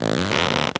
foley_leather_stretch_couch_chair_14.wav